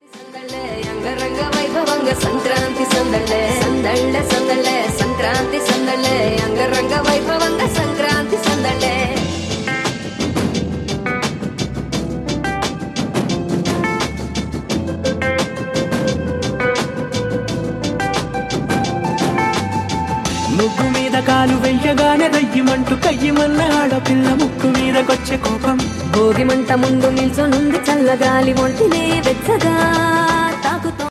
best flute ringtone download
dance ringtone download